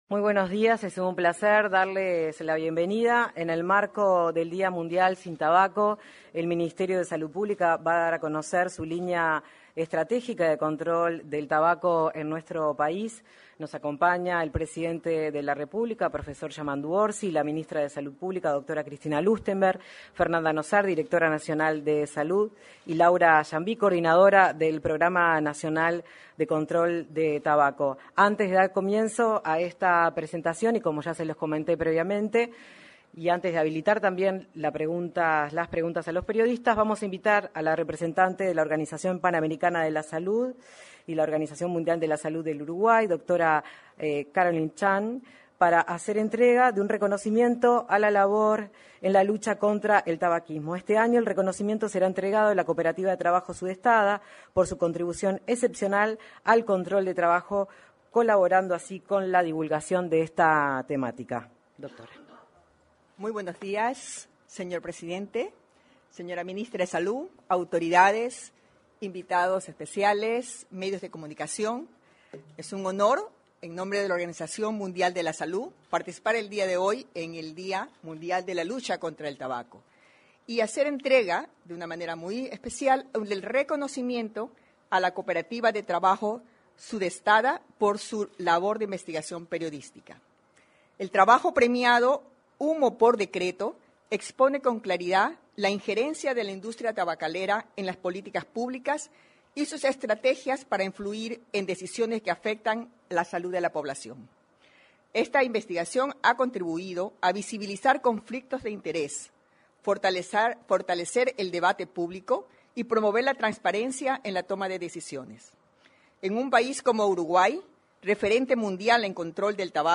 Conferencia de prensa del presidente de la República, Yamandú Orsi